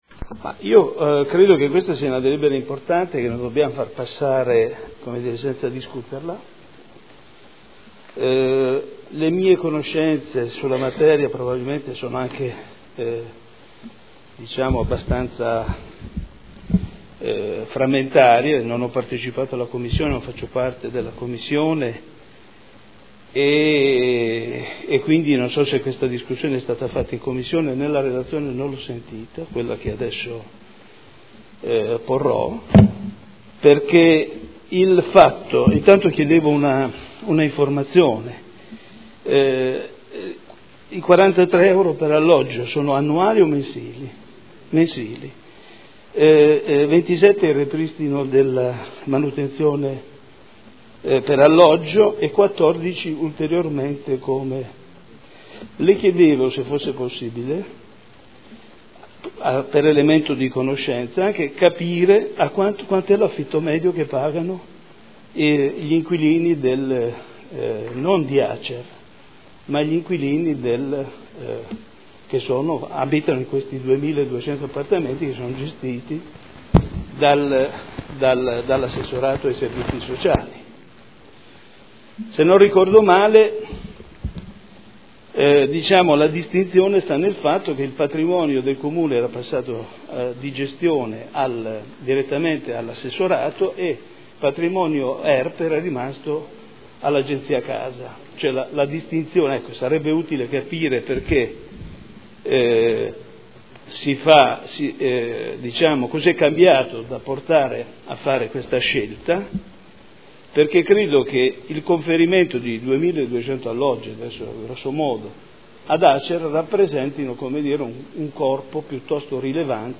Seduta del 19 dicembre. Proposta di deliberazione: Affidamento in concessione all’Azienda Casa Emilia-Romagna del servizio di gestione del patrimonio di edilizia residenziale pubblica di proprietà del Comune di Modena – Approvazione accordo quadro provinciale e contratto di servizio del Comune di Modena. Dibattito